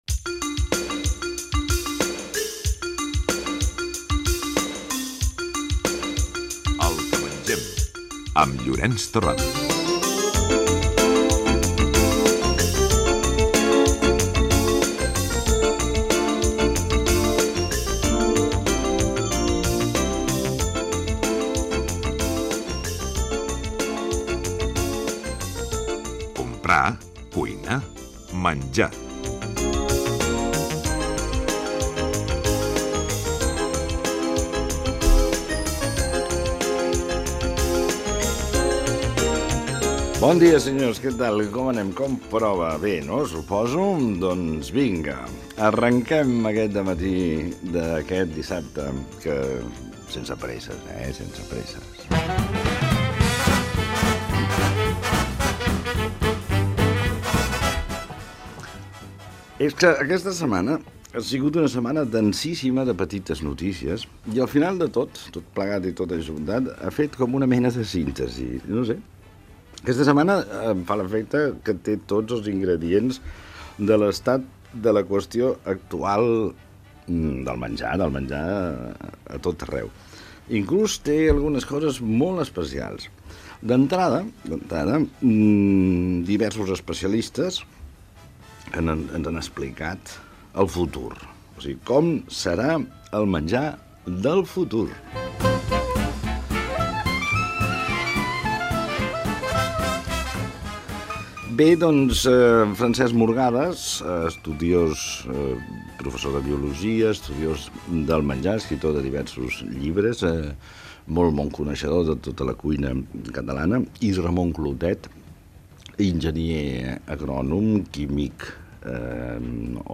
Careta del programa, presentació i comentari sobre com serà el menjar del futur
Divulgació